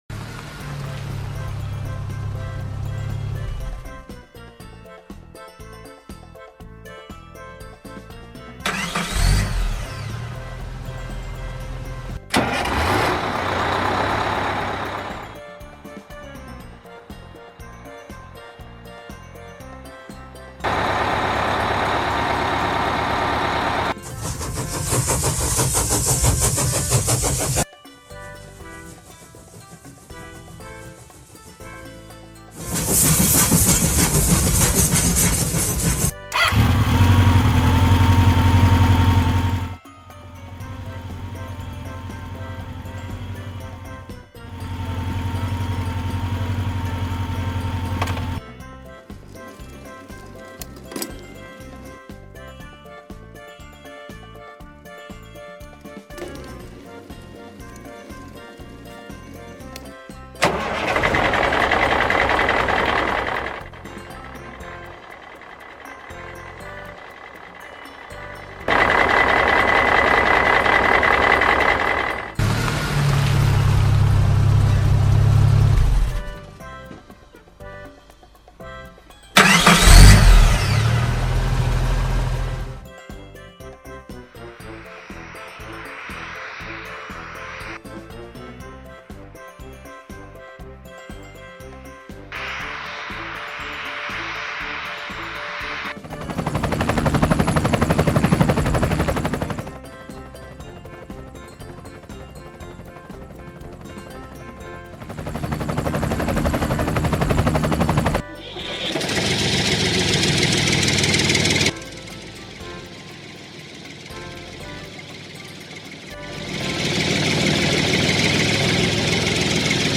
Ara anem a jugar, escolteu només l’audio i intenteu endevinar quin transport sona (si voleu podeu anar apuntant el nom en un full i després els comproveu en el vídeo anterior…)
Mitjans-de-transport.mp3